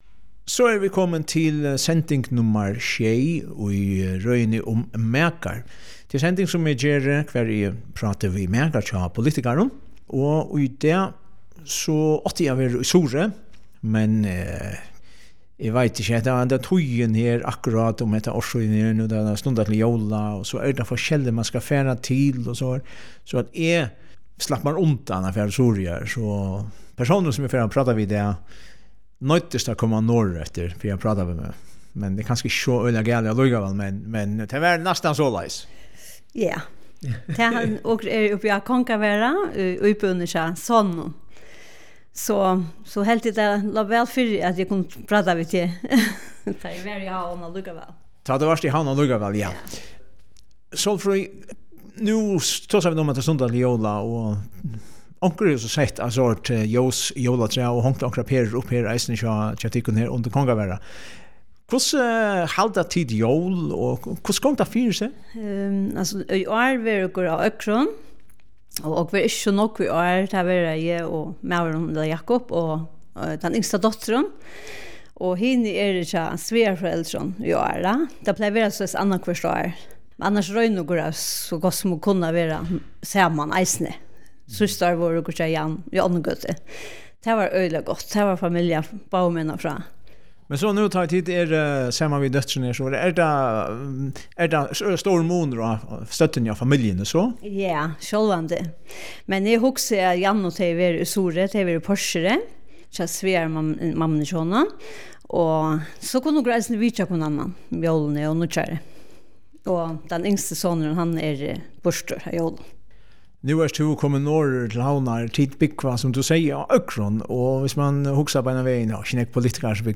Makin er røð í 12 pørtum, har prátað verður við makar hjá politikarum.